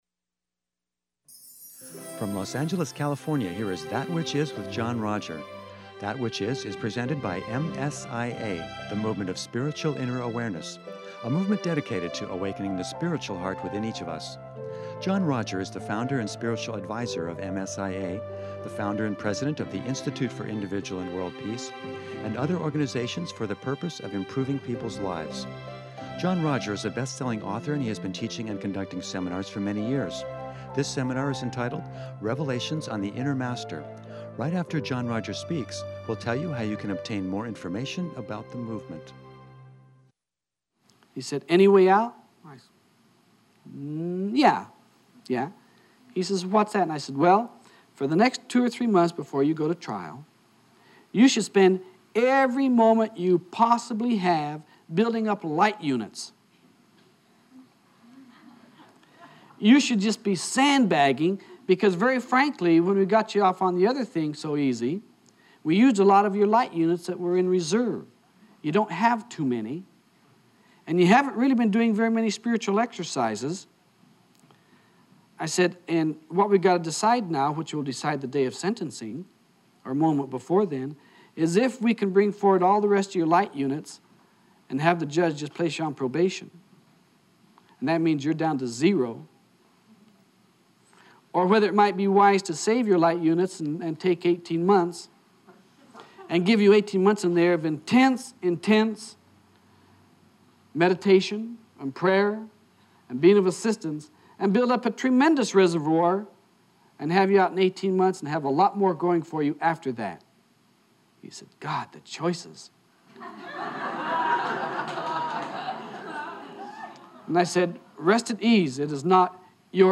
In part two of this seminar